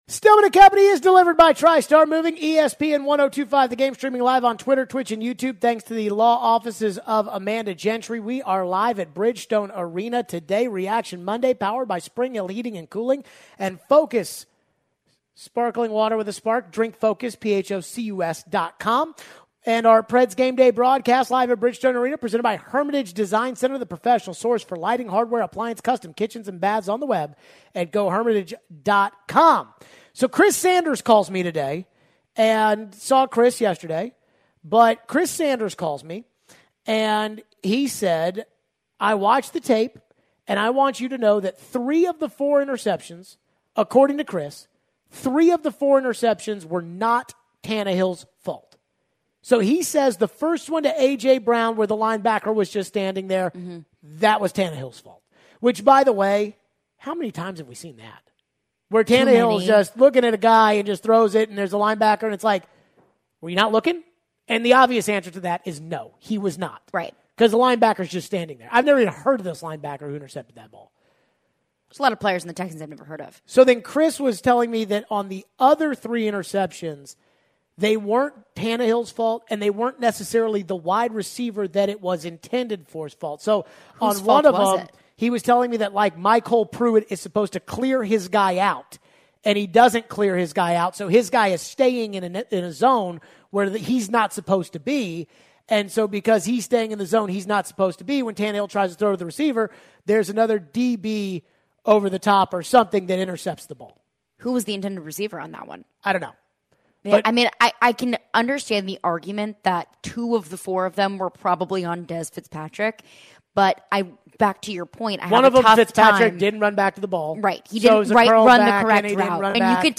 Plus back to more calls and texts.